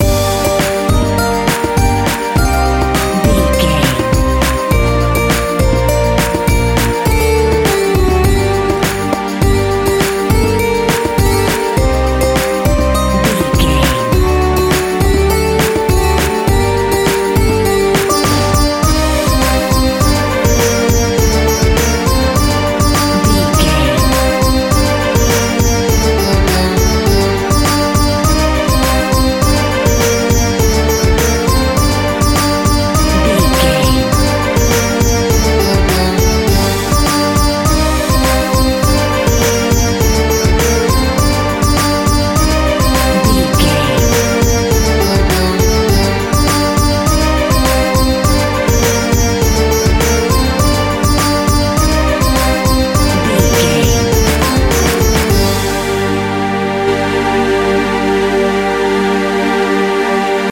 Aeolian/Minor
World Music
percussion
congas
bongos
djembe